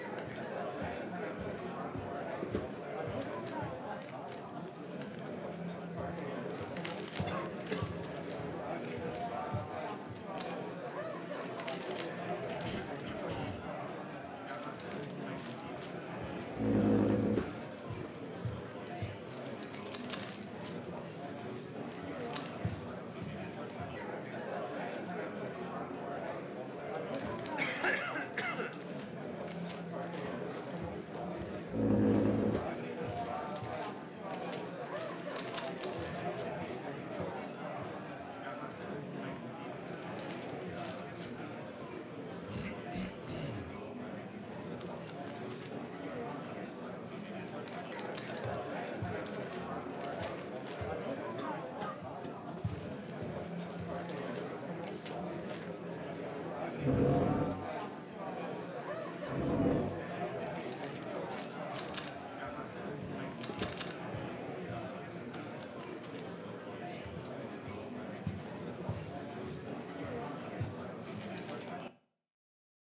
Audio material from the game
Ambientsound Courtroom 600